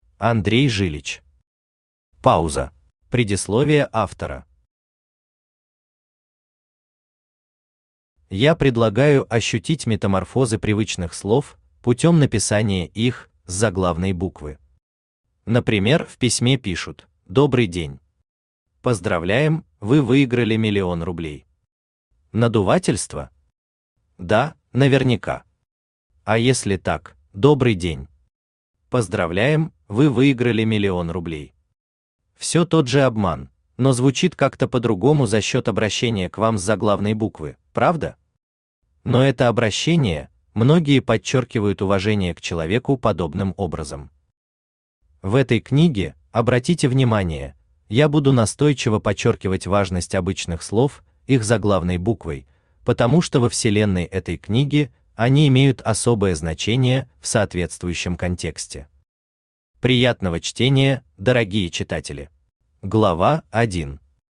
Аудиокнига Пауза | Библиотека аудиокниг
Aудиокнига Пауза Автор Андрей Жилич Читает аудиокнигу Авточтец ЛитРес.